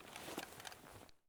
EFT Aim Rattle / gamedata / sounds / weapons / rattle / raise / raise_6.ogg